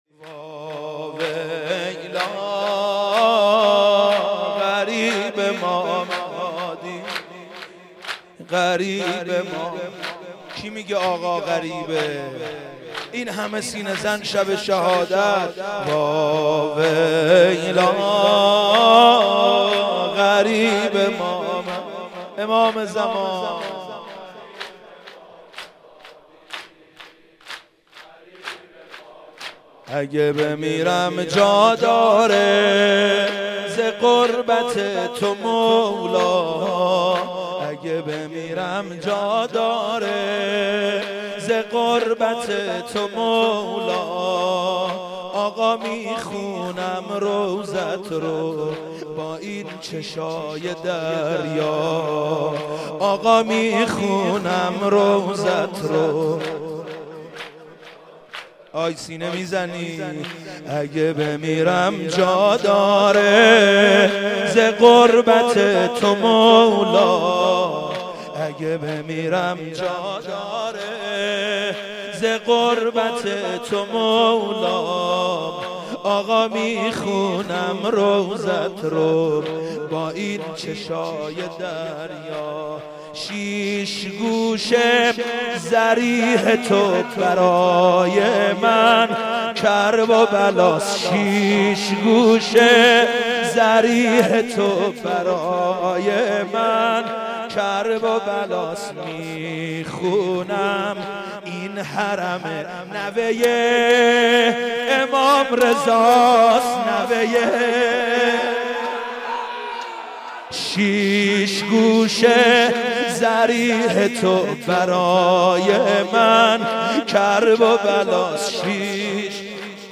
مداحی
بمناسبت شهادت امام هادی (ع)